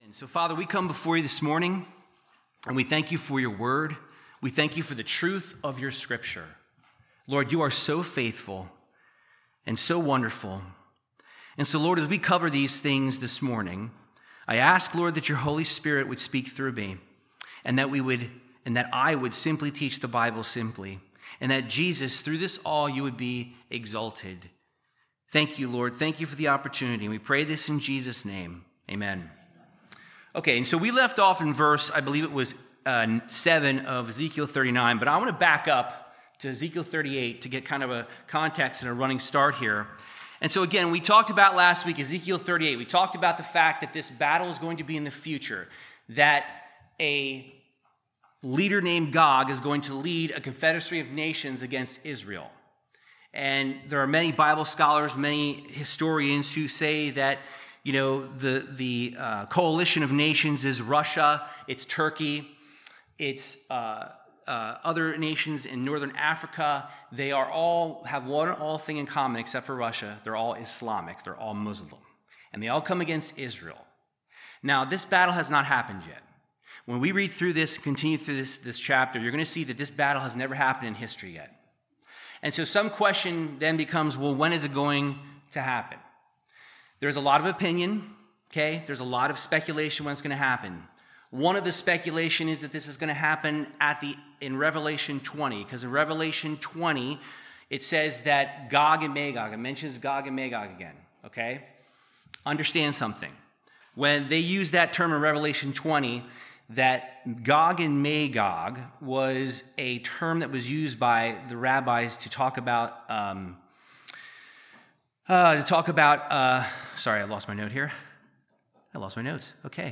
Topical Bible Teaching on The End Times. Part 4 continues the discussion of the battle described in Ezekiel 38-39